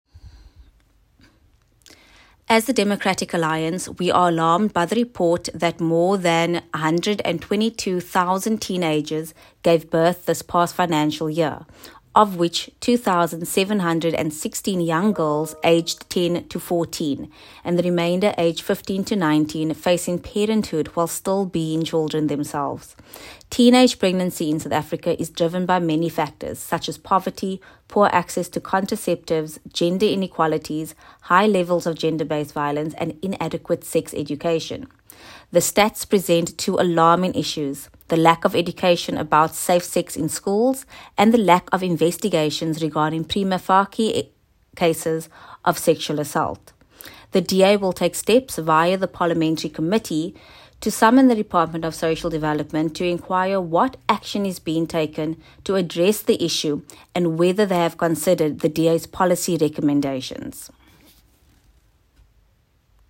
soundbite by Alexandra Abrahams MP